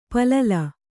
♪ palala